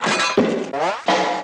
golpes cartoon quilombo 02